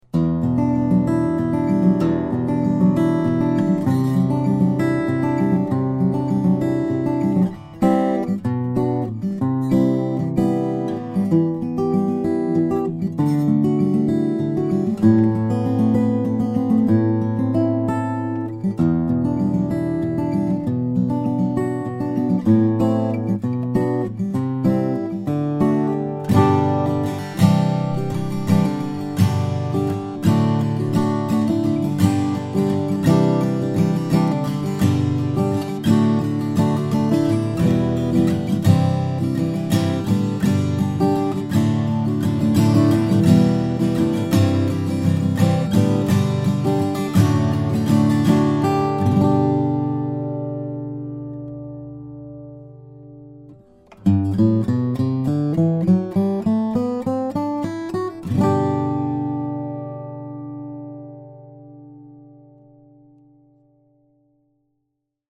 Here’s a stunning Maingard OM in Brazilian Rosewood and German Spruce, a combination that is sure to please. This guitar has a clear and strong voice, with the articulation fingerstyle players demand and a great strummed sound as well.